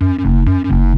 Wobble Bass.wav